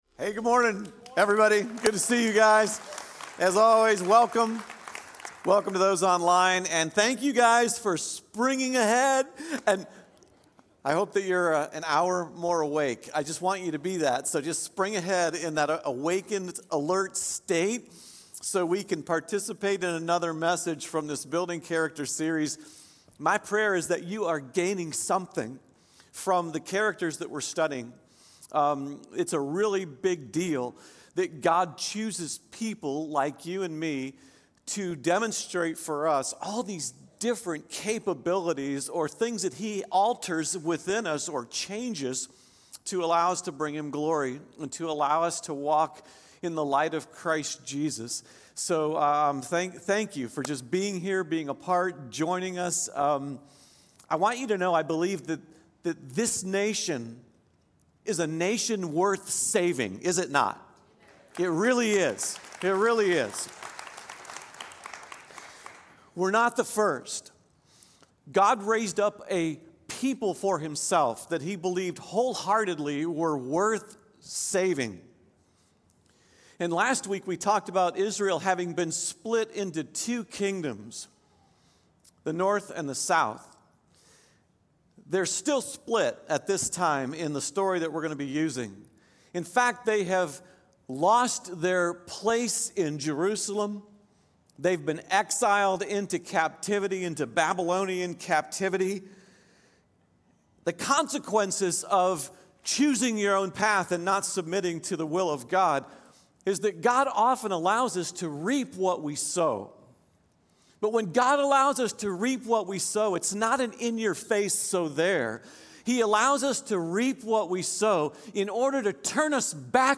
Building Character #8 | Esther, sermon